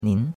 nin2.mp3